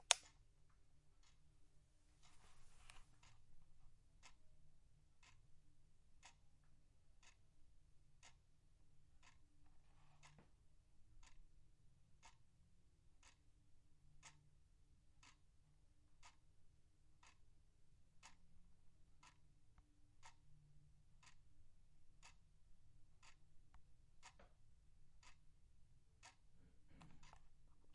滴答滴答乌尔莱比锡
描述：单声道录制的客厅里的挂钟滴答声